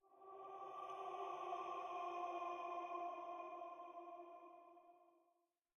Minecraft Version Minecraft Version snapshot Latest Release | Latest Snapshot snapshot / assets / minecraft / sounds / ambient / nether / soulsand_valley / voices4.ogg Compare With Compare With Latest Release | Latest Snapshot